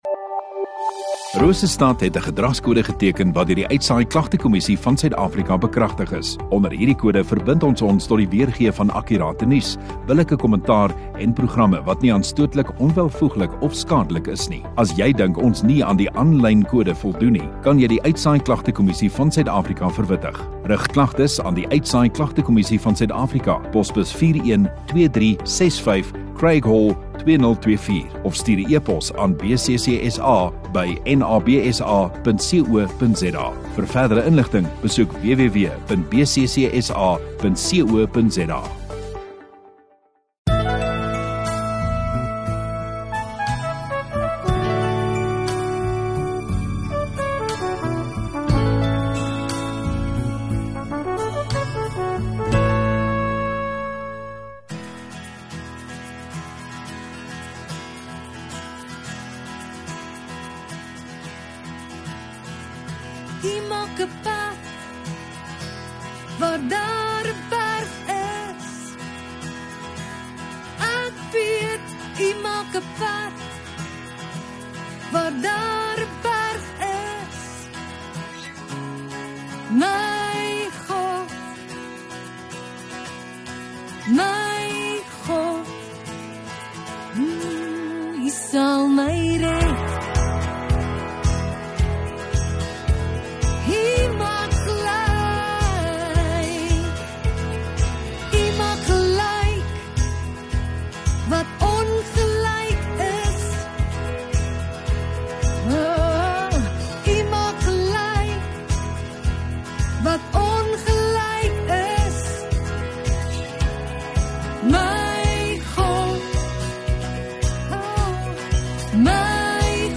Sondagoggend Erediens